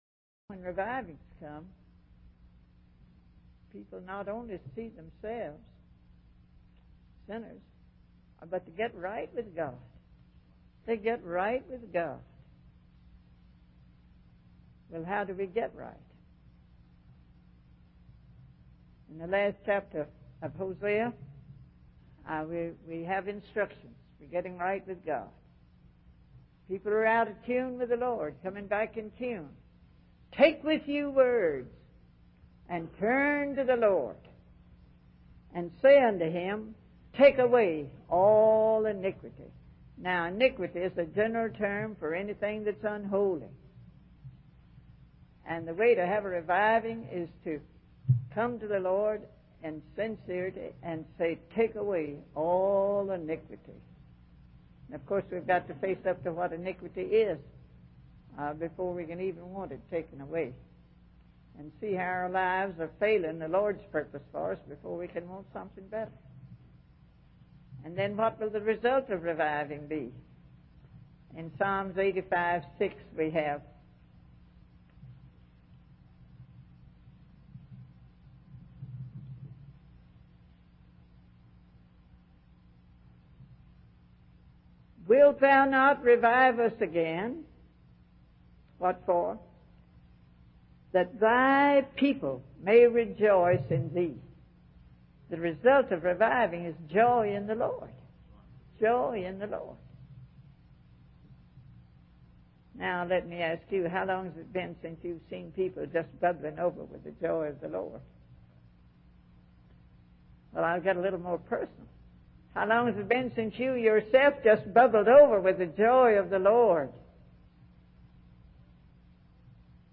In this sermon, the speaker begins by expressing a moment of realization and conviction among the congregation. They reflect on their inconsistency in seeking holiness and confessing sin, particularly in their missionary work. The speaker then instructs the congregation to make a personal list of everything in their hearts and lives that is unholy, as a form of self-examination and repentance.